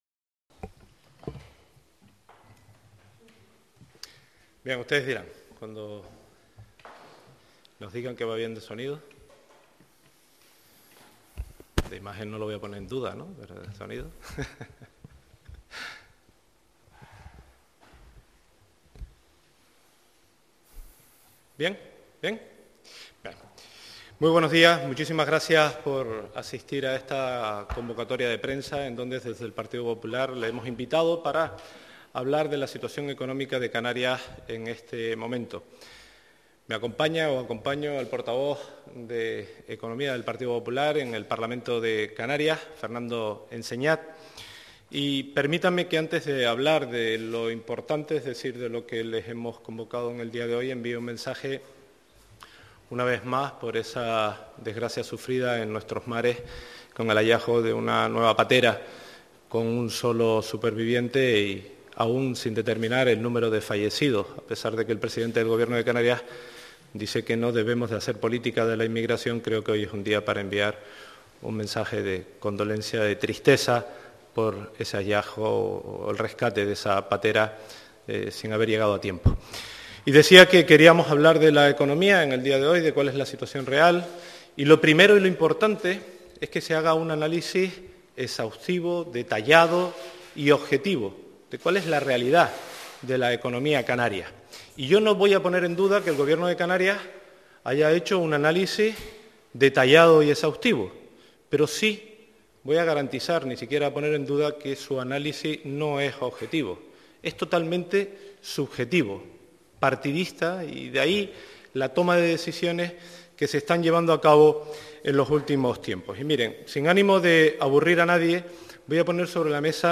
Rueda de prensa del GP Popular sobre análisis de la situación económica - 11:00